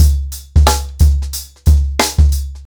TheStakeHouse-90BPM.39.wav